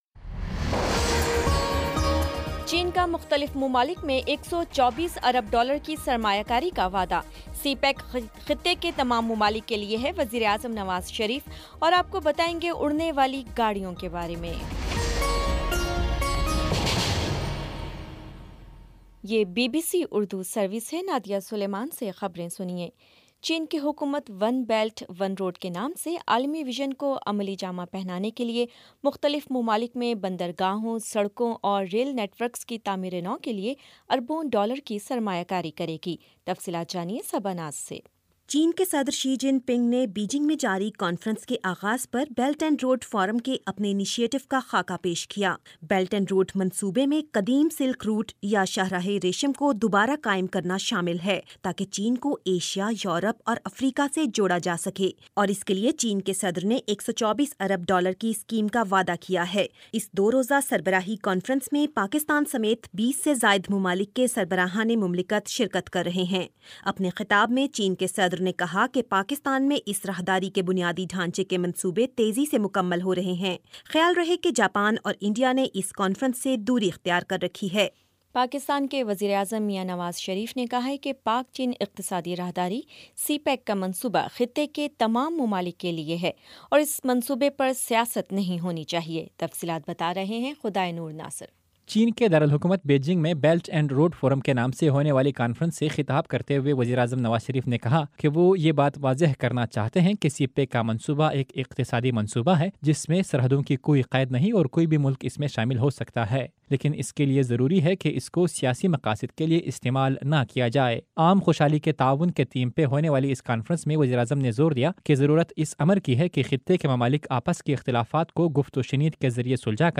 مئی 14 : شام سات بجے کا نیوز بُلیٹن